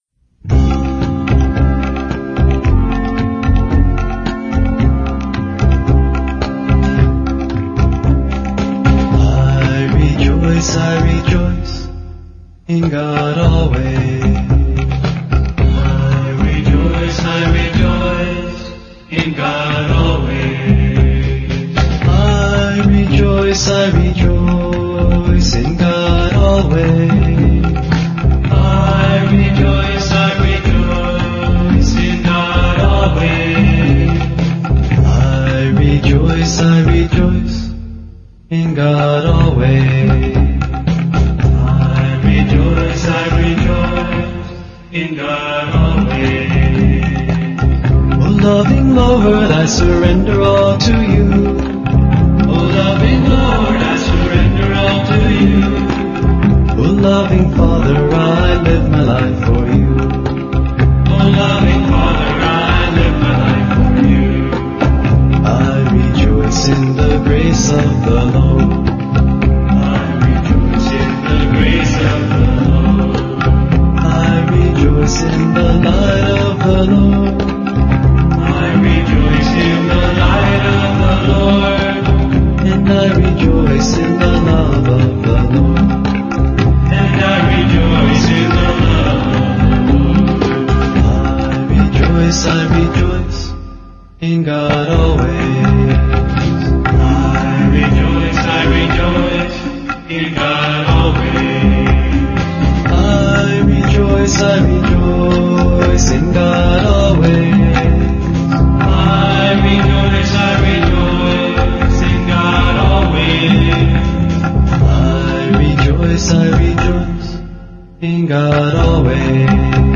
1. Devotional Songs
Major (Shankarabharanam / Bilawal)
8 Beat / Keherwa / Adi
Medium Fast
3 Pancham / E
7 Pancham / B